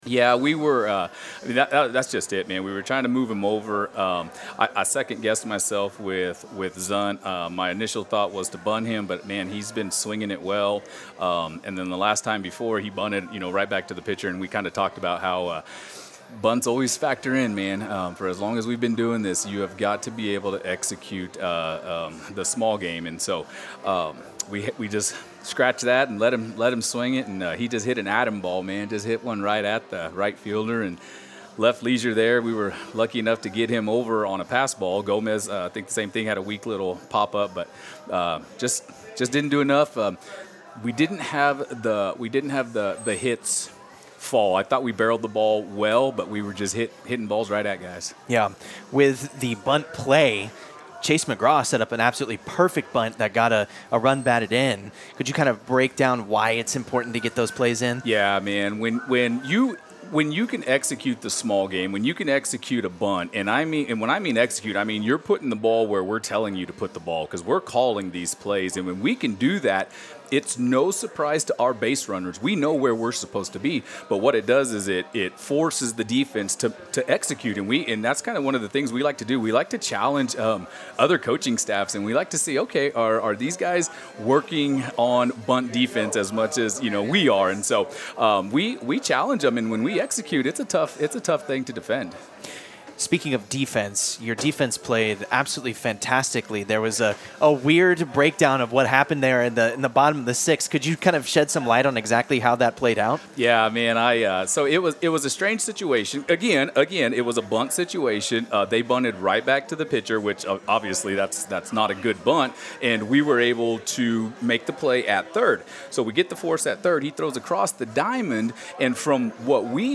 0516-Postgame-Interview.mp3